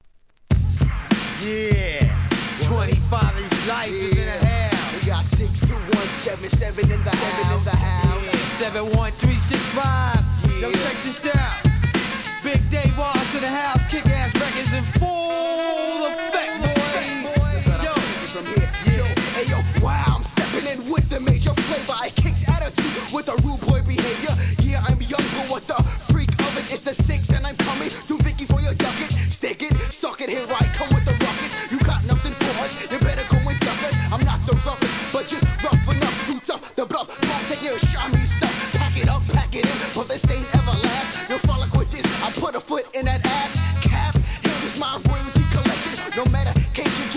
HIP HOP/R&B
DOPEハーコー・アングラ!!!